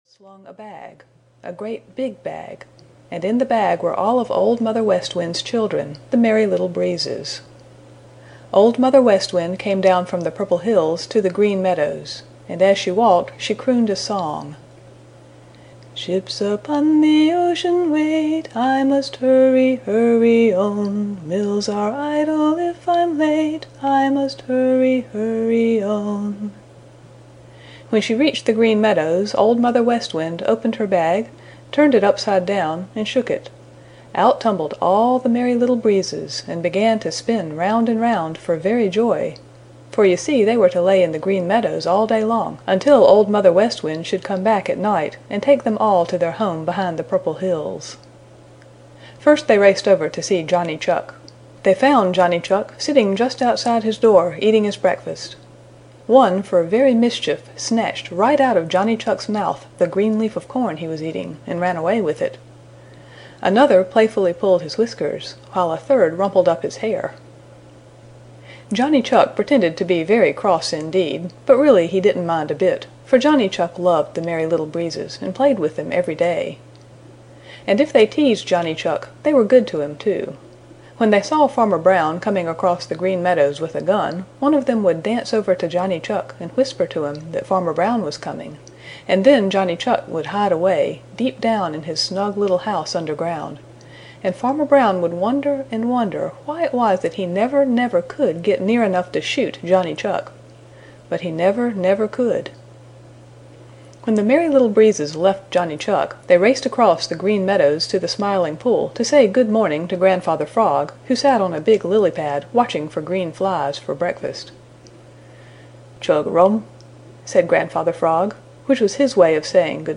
Old Mother West Wind (EN) audiokniha
Ukázka z knihy